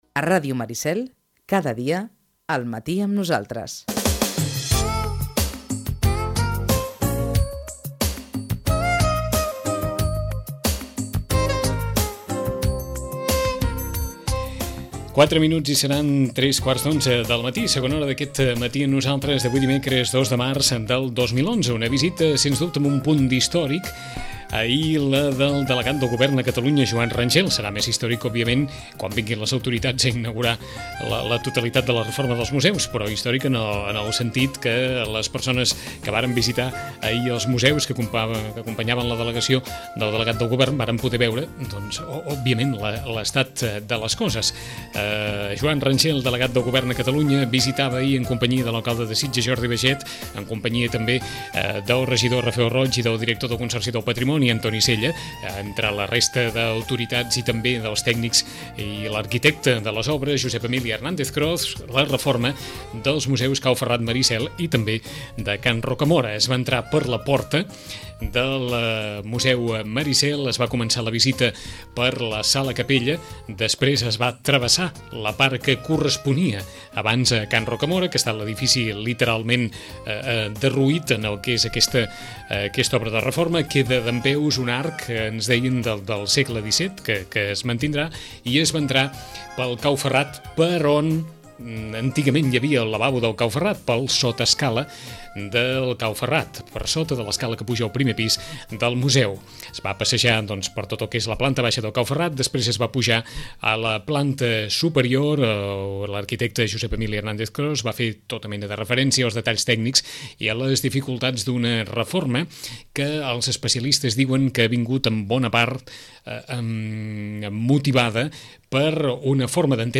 El delegat del govern a Catalunya, Joan Rangel, ha visitat les obres de remodelació dels museus sitgetans, en companyia de les autoritats locals i dels tècnics. En roda de premsa, l’alcalde Baijet i el mateix Joan Rangel valoraren l’estat de les obres i la decisió del fiscal d’urbanisme de Barcelona d’arxivar la denúncia feta per la plataforma SOS Sitges.